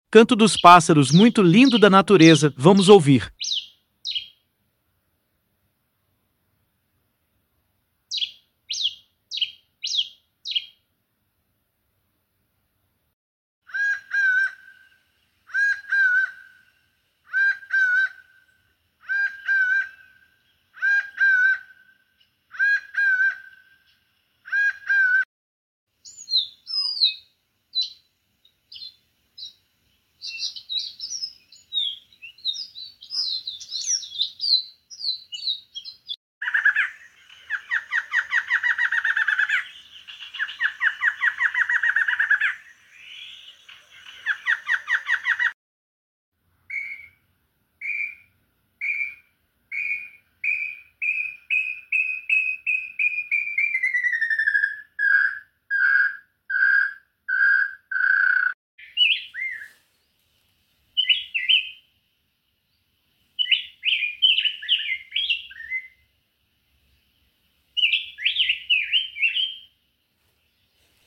canto dos pássaros muito lindo sound effects free download
canto dos pássaros muito lindo do Brasil